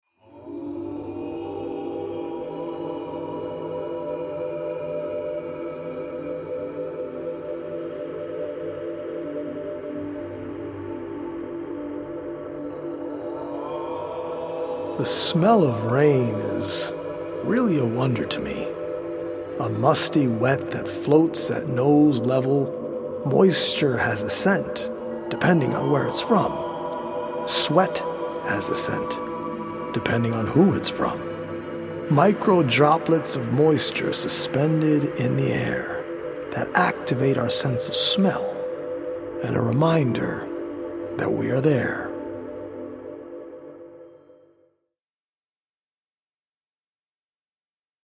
healing Solfeggio frequency music
EDM